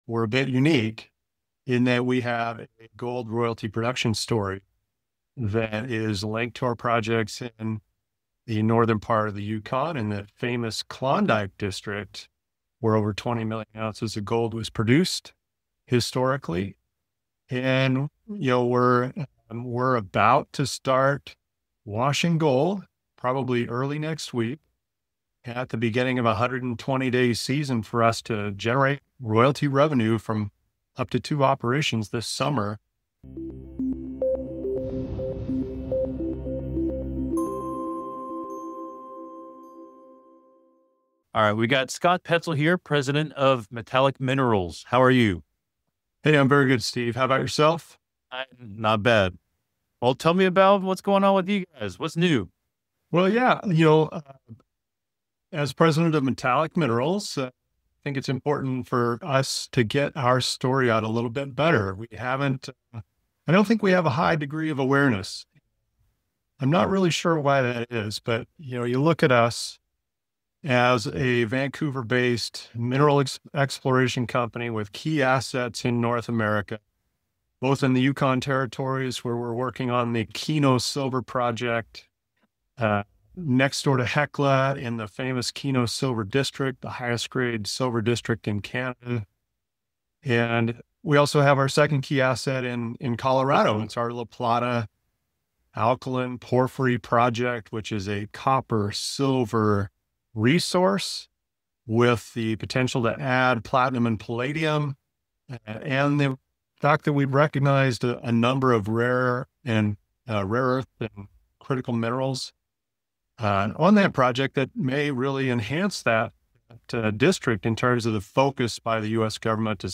Natural Resource Stocks Exclusive Interview